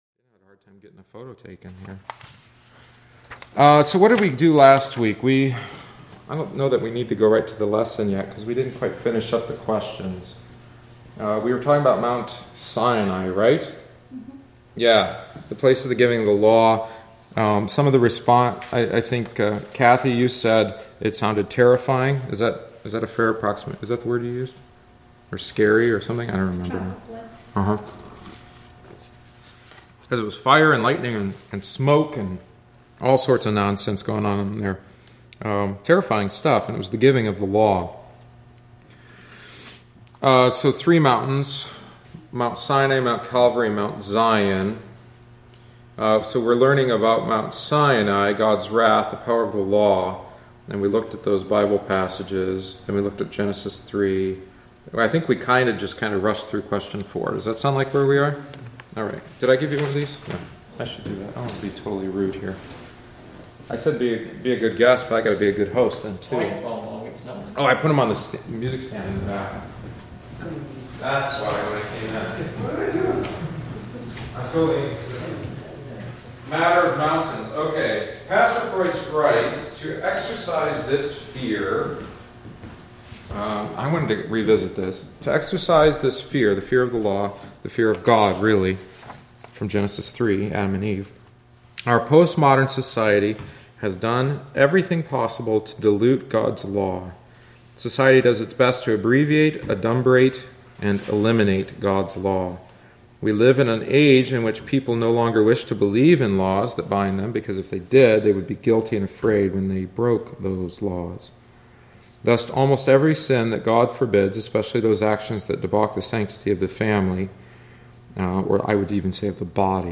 The attached audio omits the text of the book and includes only our discussion.